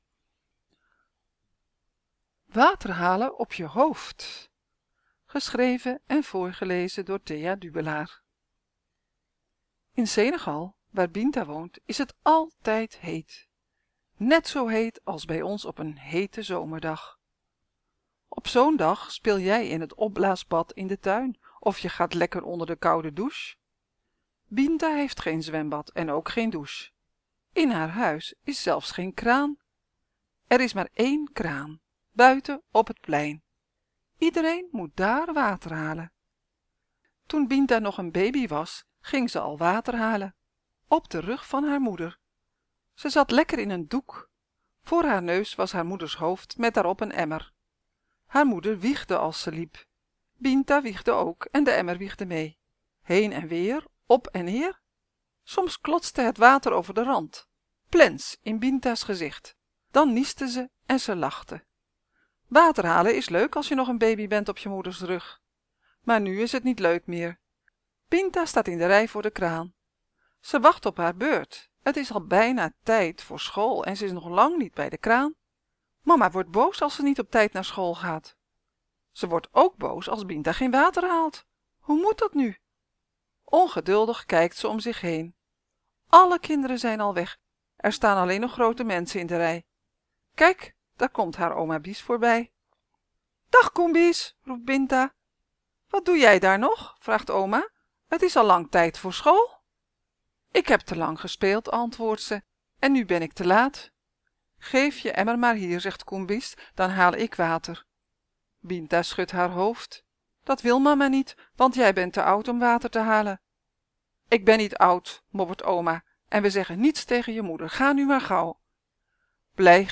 In de Kinderboekenweek lees ik elke dag een verhaal voor uit de bundel ‘Vliegeren’. Vandaag een verhaal over Binta die thuis geen kraan heeft.